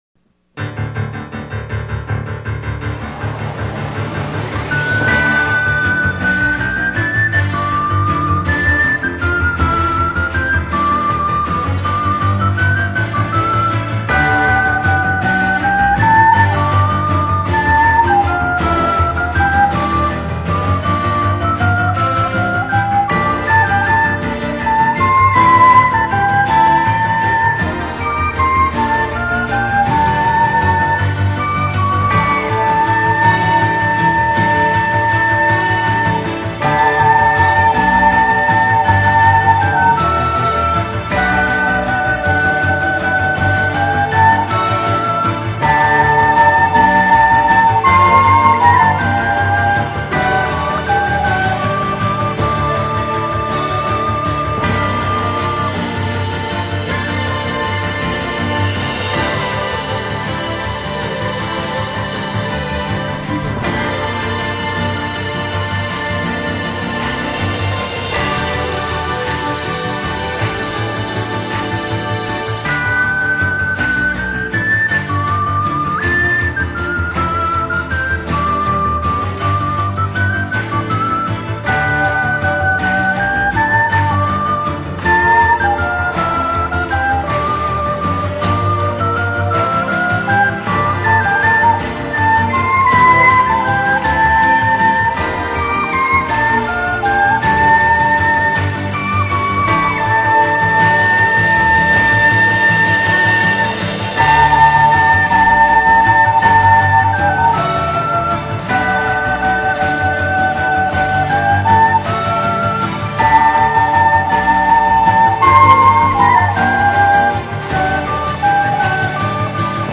오카리나 연주곡 베스트 모음